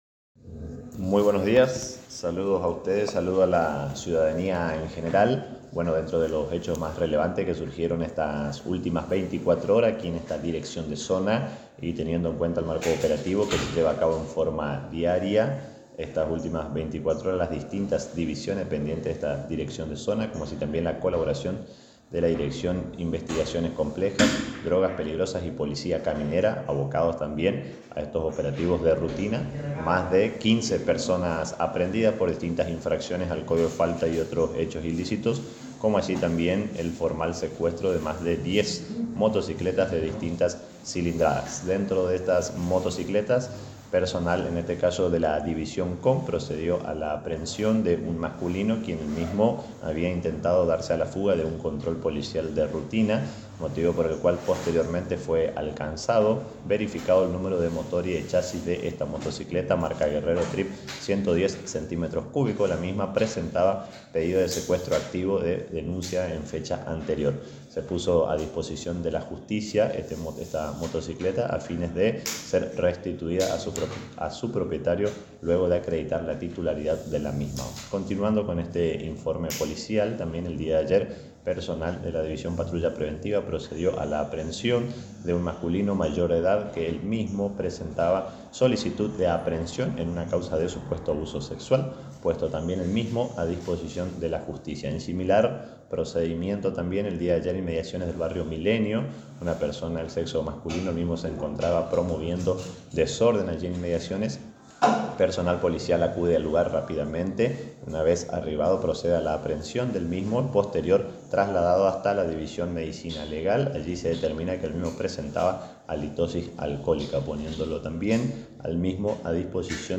Informe policial de la Dirección Zona Interior Sáenz Peña
Informe-Policial-Viernes-01-07.mp3